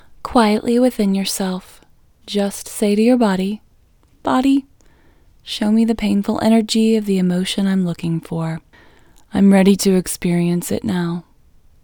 LOCATE Short IN English Female 9